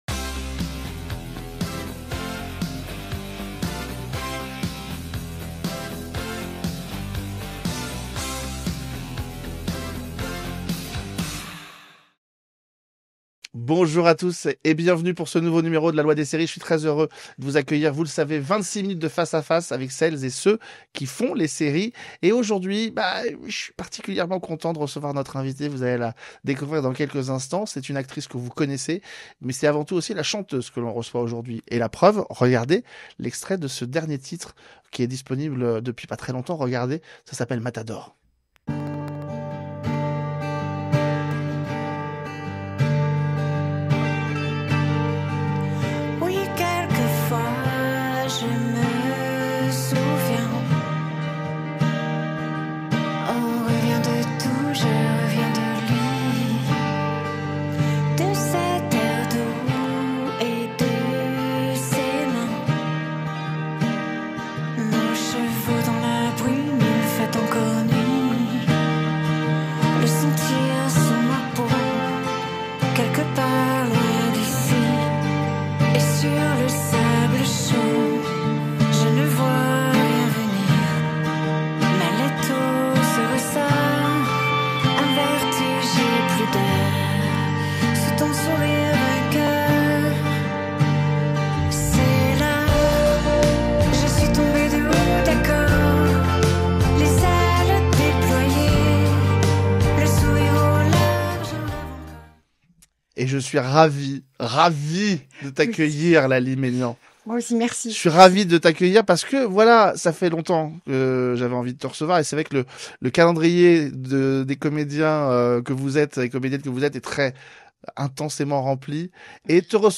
L’invitée : Laly Meignan